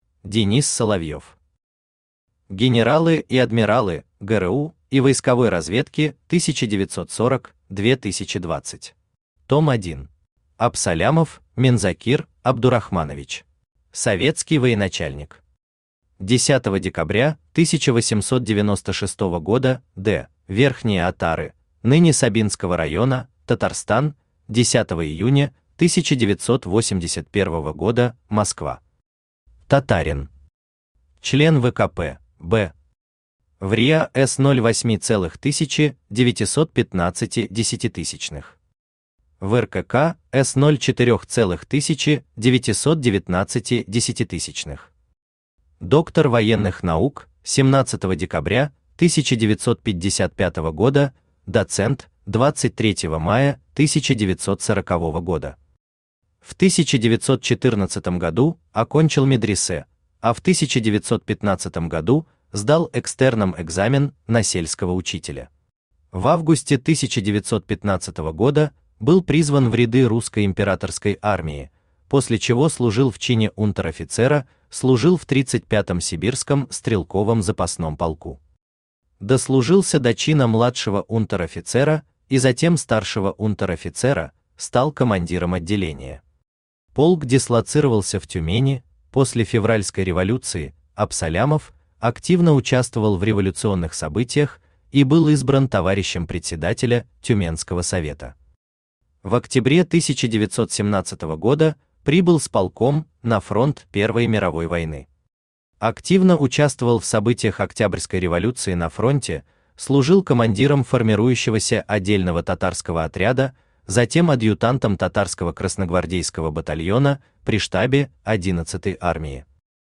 Aудиокнига Генералы и адмиралы ГРУ и войсковой разведки 1940-2020. Том 1 Автор Денис Соловьев Читает аудиокнигу Авточтец ЛитРес.